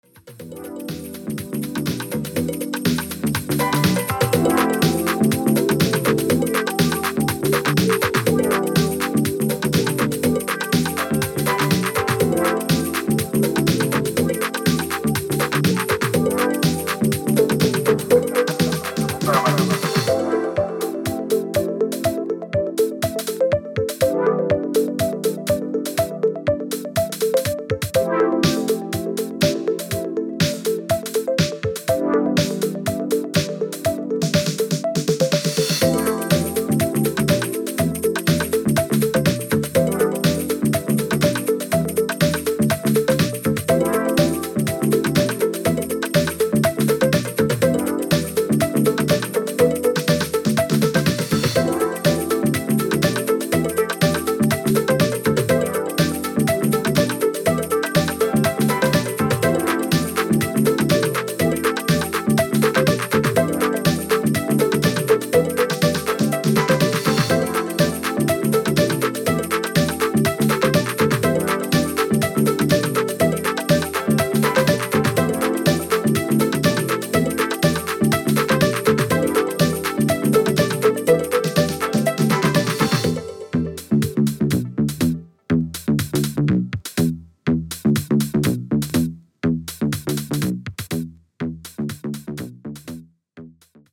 時間軸関係ない長く使えるDeep House 4曲収録12"です。